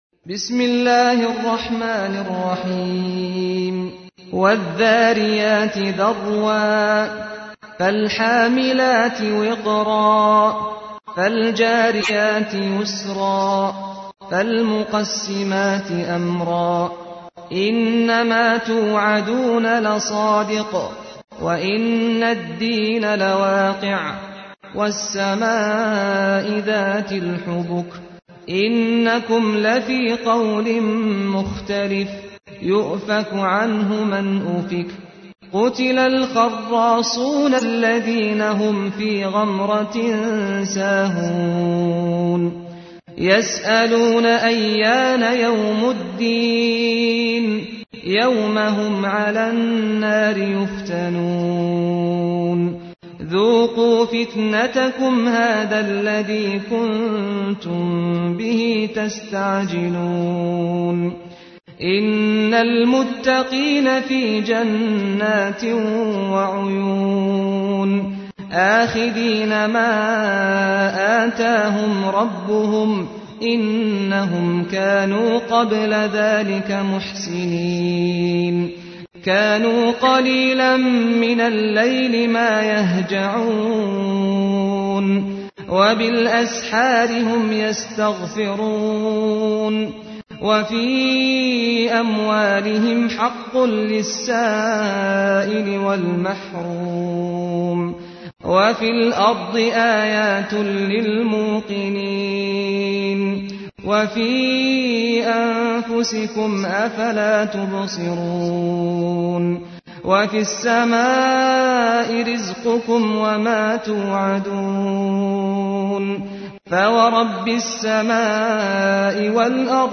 تحميل : 51. سورة الذاريات / القارئ سعد الغامدي / القرآن الكريم / موقع يا حسين